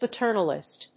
音節pa･ter･nal･ist発音記号・読み方pətə́ːrn(ə)lɪst